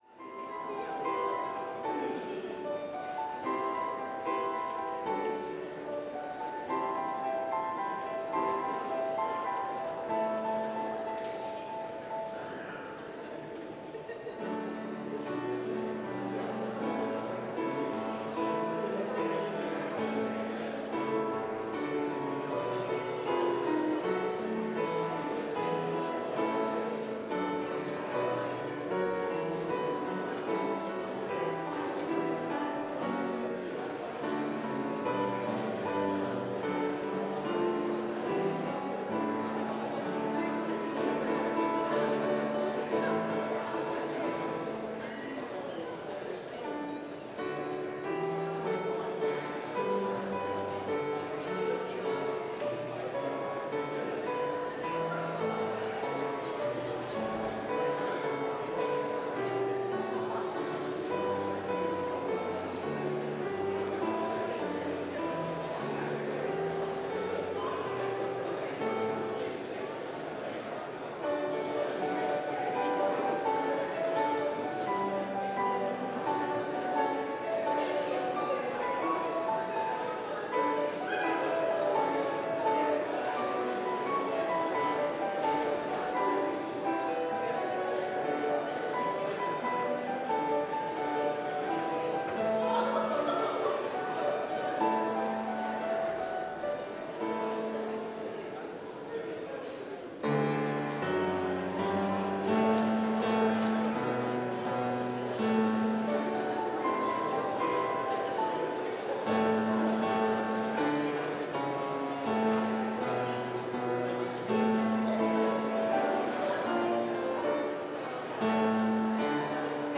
Complete service audio for Chapel - Friday, February 7, 2025